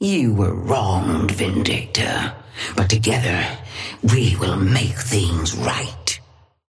Sapphire Flame voice line - You were wronged, Vindicta. But together, we will make things right.
Patron_female_ally_hornet_start_01.mp3